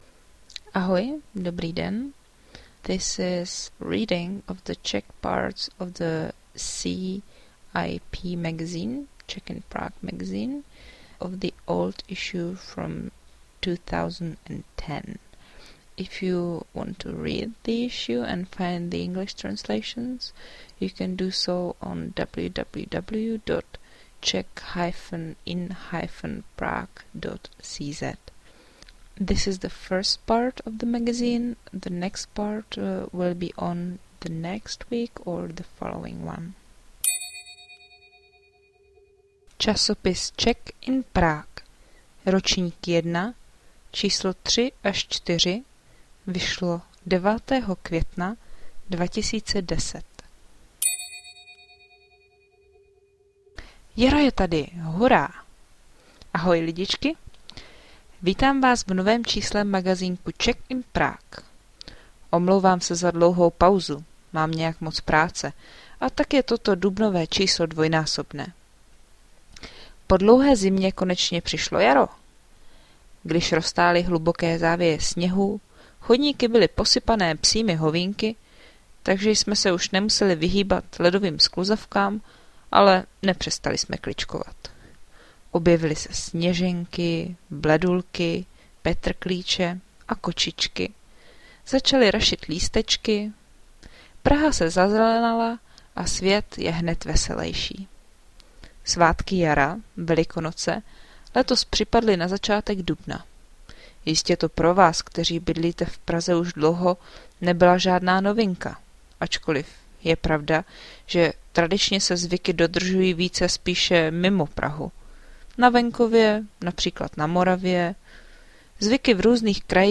In this old issue of the CIP magazine, you’ll find recipes for lunch written by my students, some information about how Czechs celebrate Easter, and about other festivals or celebrations in April and May. This is the first part of the reading.